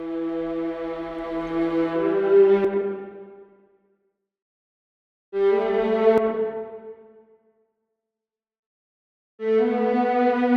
Обнаружилась такая проблема: В проекте несколько дорожек CSS, струнные играют картошки на легато и вместо нескольких ровных нот без каких-либо пауз CSS в рипере с недавнего времени почему-то начали выдавать то, что в примере.